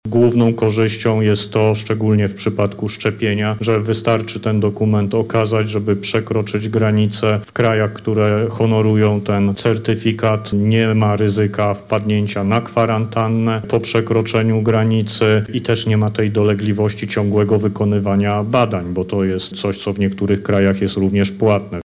Do tej pory odnotowaliśmy ponad 7 milionów pobrań – mówi minister Niedzielski: